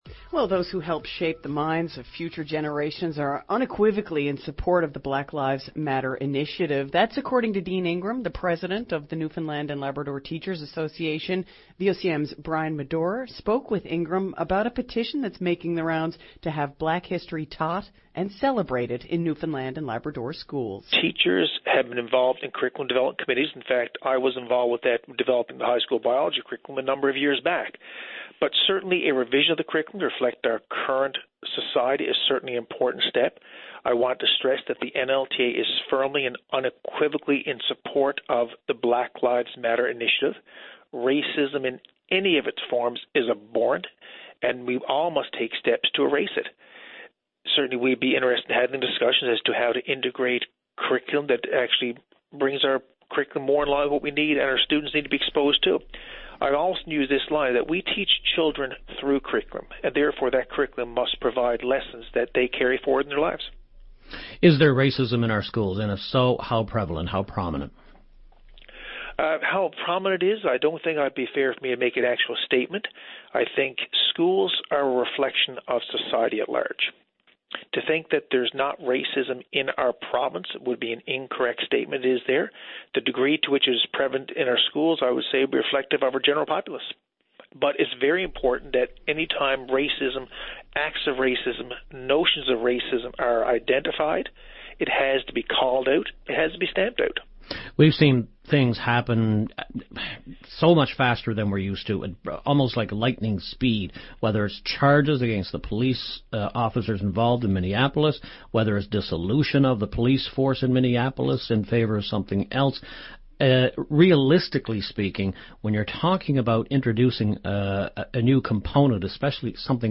Media Interview - VOCM Morning Show June 9, 2020